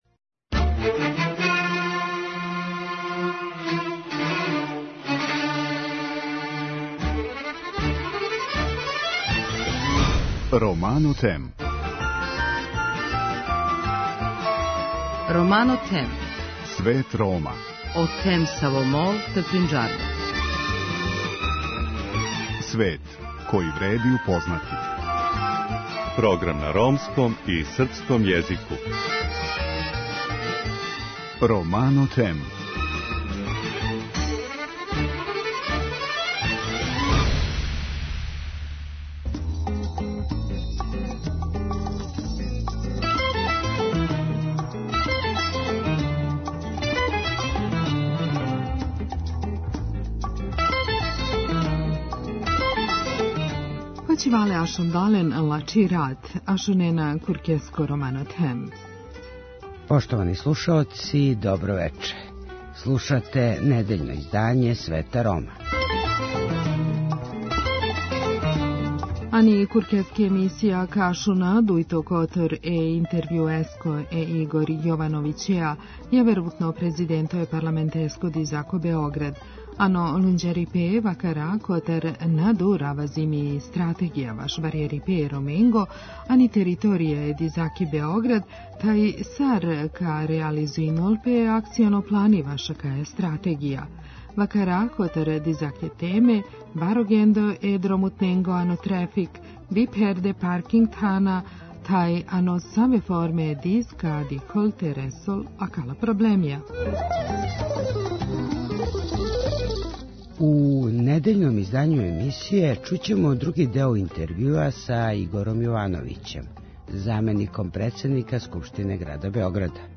Игор Јовановић, заменик председника Скупштине града Београда, интервју